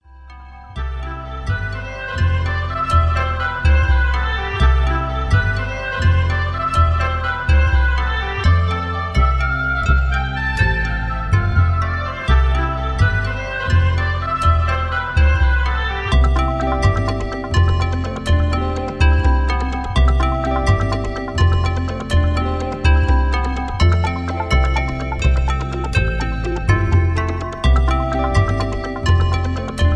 busy background music ambient sounds mallets, strat, horn,